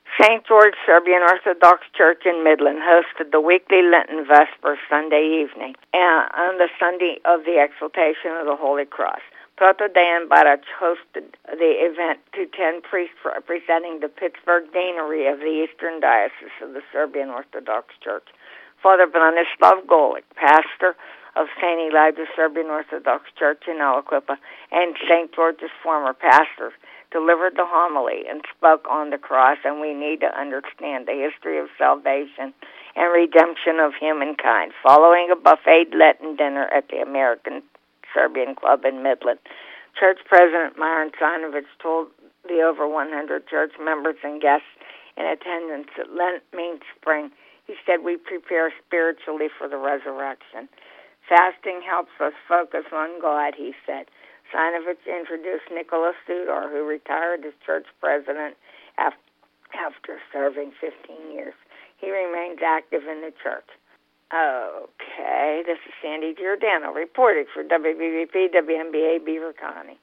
St. George Serbian Orthodox Church Holds Lenten Vespers